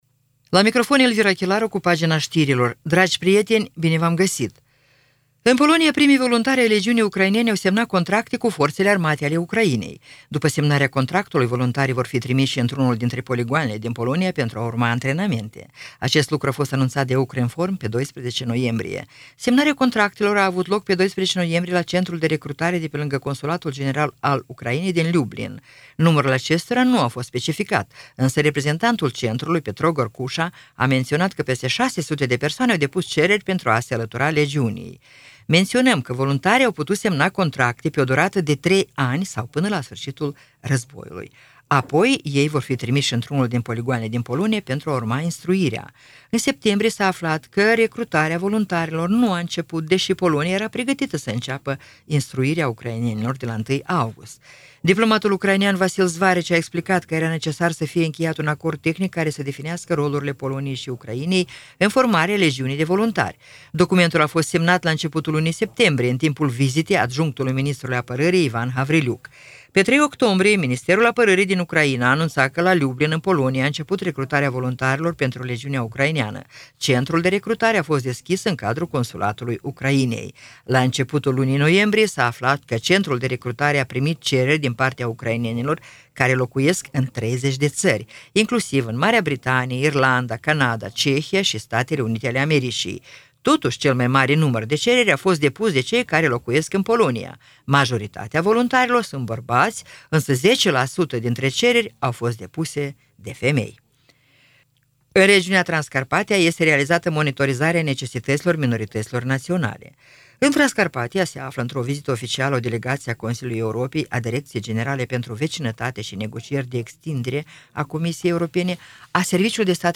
Știri Radio Ujgorod – 13.11.2024